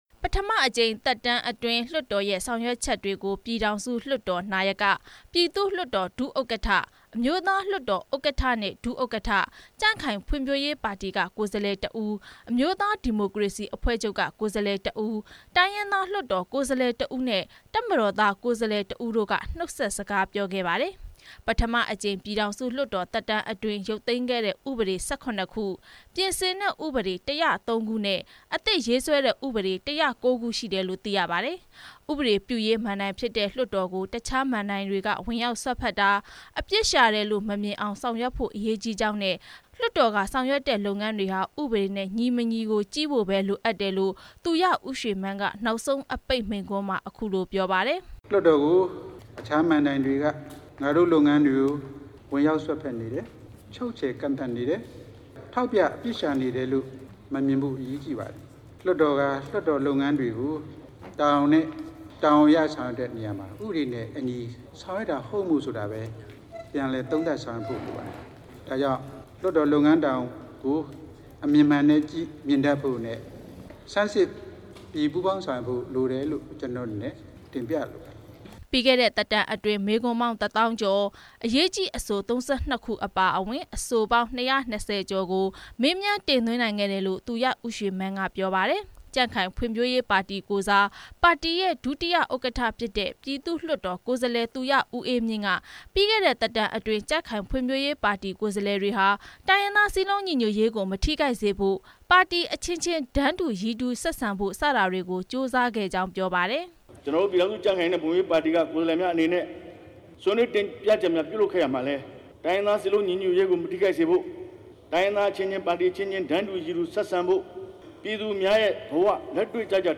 ဒီကနေ့ ပြည်ထောင်စုလွှတ်တော် နောက်ဆုံးနေ့မှာ လွှတ်တော်သက်တမ်းအတွင်း အတွေ့အကြုံတွေကို ပြည်ထောင်စုလွှတ်တော် နာယက သူရဦးရွှေမန်းနဲ့ ကိုယ်စားလှယ်တချို့က နှုတ်ခွန်းဆက်စကား ပြောခဲ့ကြပါတယ်။